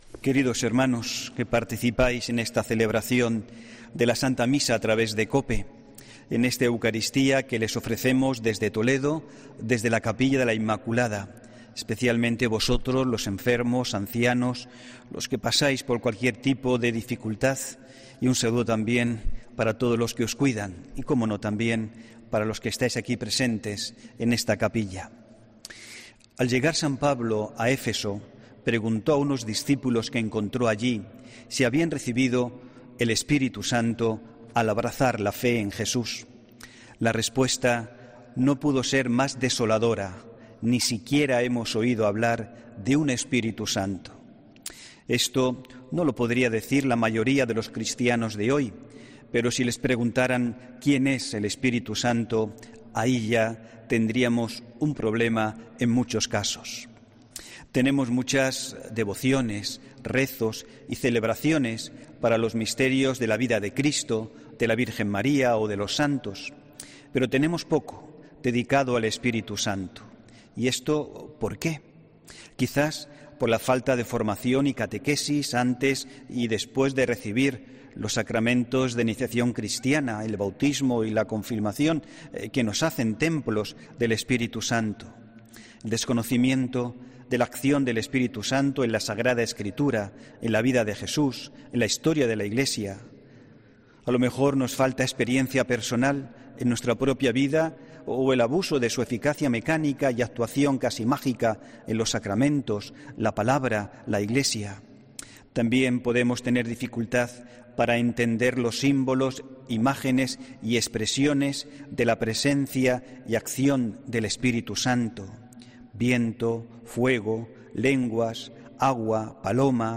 HOMILÍA 23 MAYO 2021